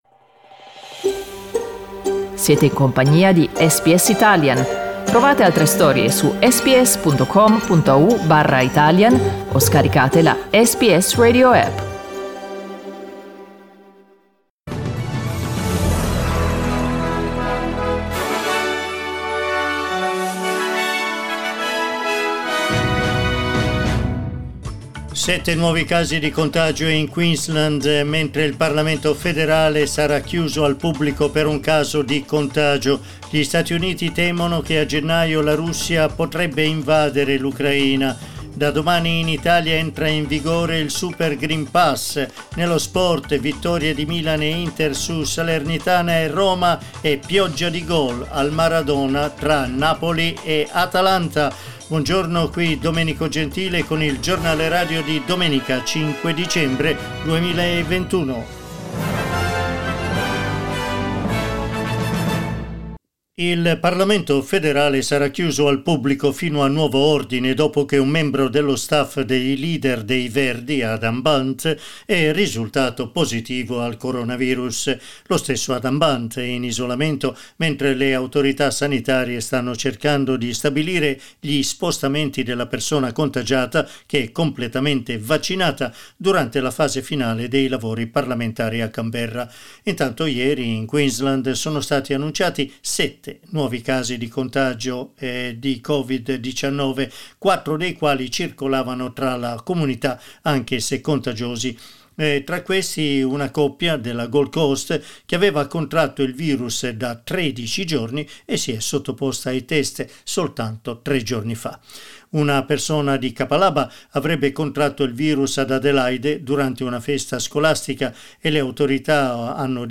Giornale radio domenica 5 dicembre 2021
Il notiziario di SBS in italiano.